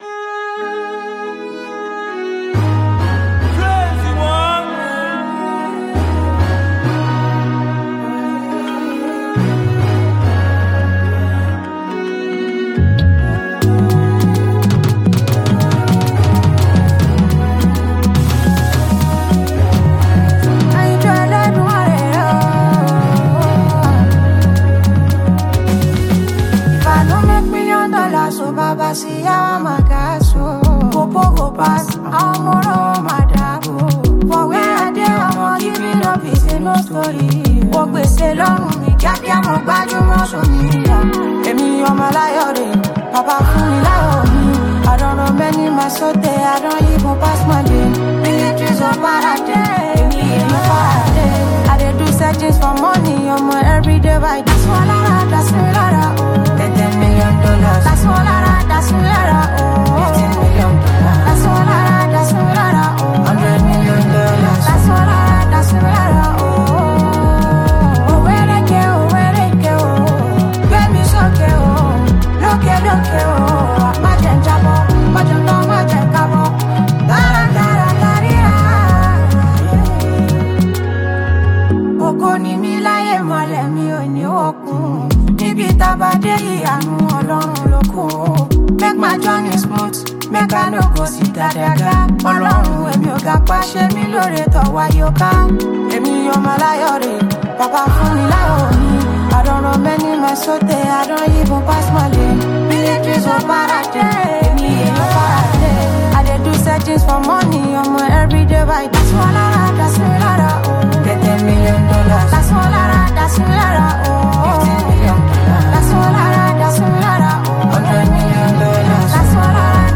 Fast-rising Nigerian singer and songwriter
Afrobeats